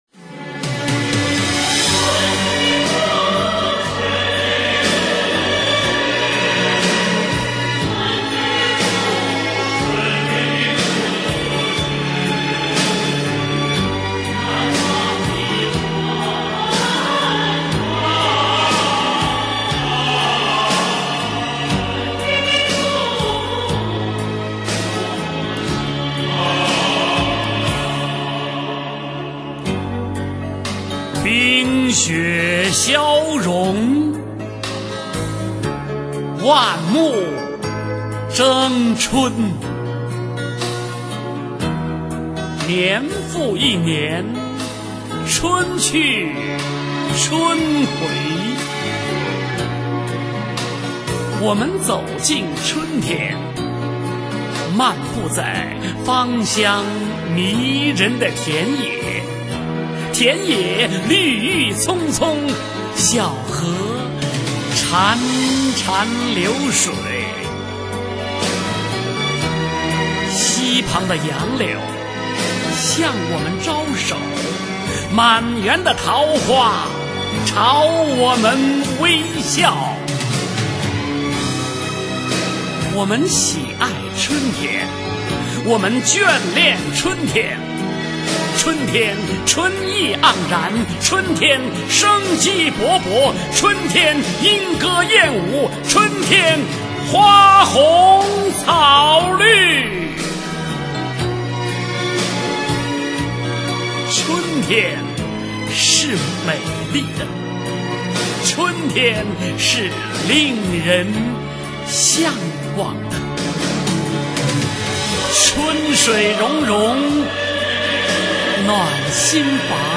走进春天　　朗诵